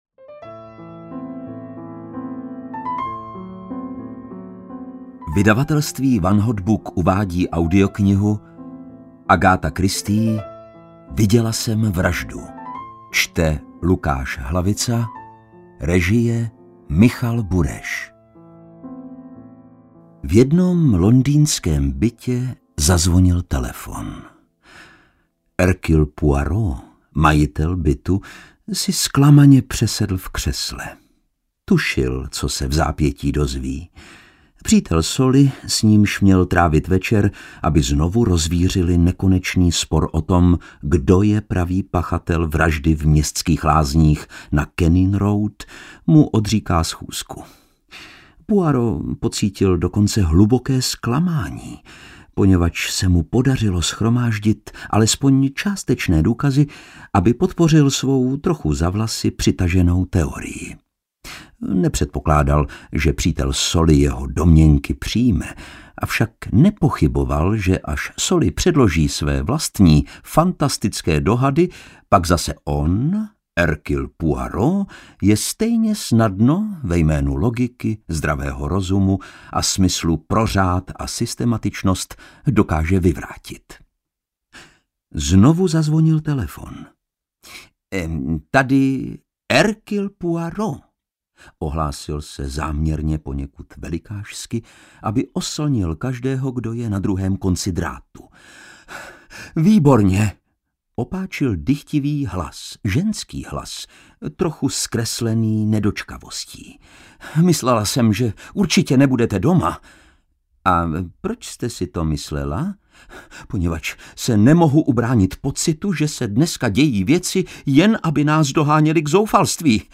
Viděla jsem vraždu audiokniha
Ukázka z knihy